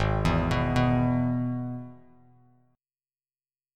G#m#5 chord